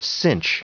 Prononciation audio / Fichier audio de CINCH en anglais
Prononciation du mot cinch en anglais (fichier audio)